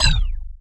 匕首 " 01DAGGE R01
描述：这些是真正的匕首相互撞击的声音。已经做了最小的编辑：分离的样本，一些EQ，一些淡化......主要是在露天嘈杂的市场环境中的原始。
标签： 匕首
声道立体声